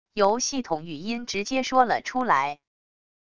由系统语音直接说了出来wav音频